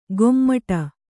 ♪ gommaṭa